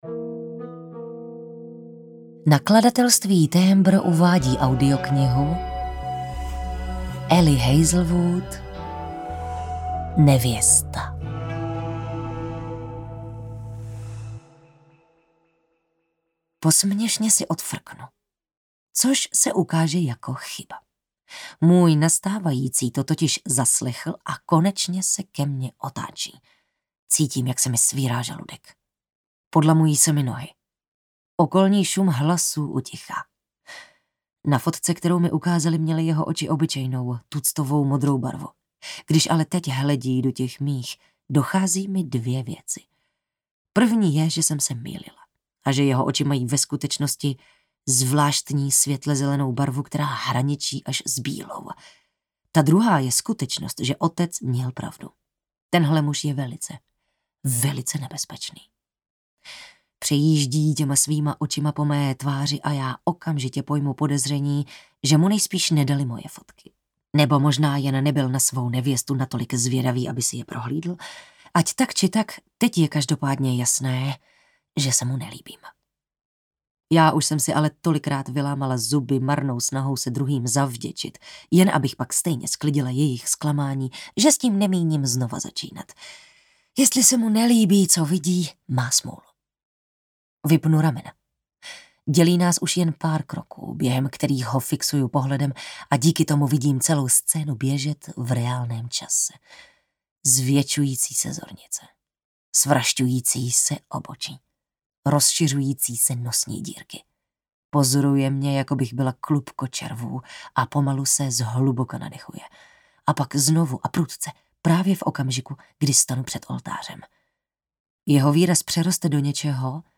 Nevěsta audiokniha
Ukázka z knihy